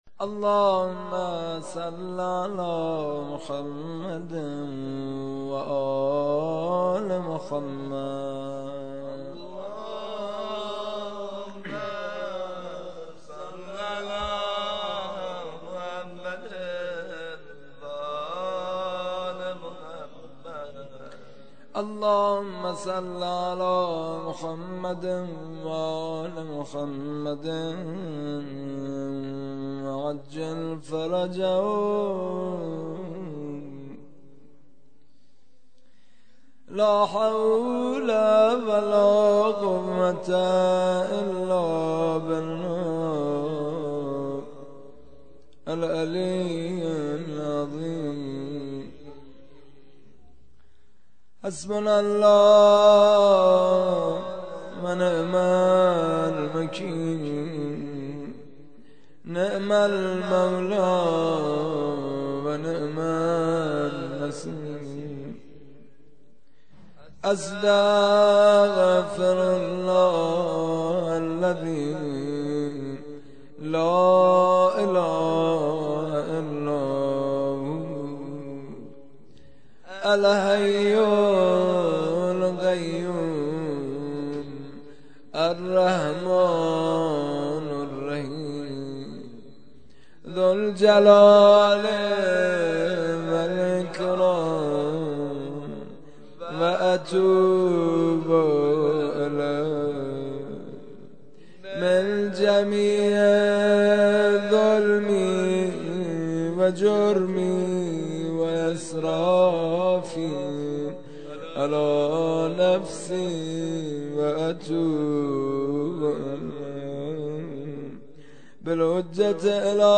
عزاداری شب دهم محرم ۱۴۲۵؛ شب عاشورا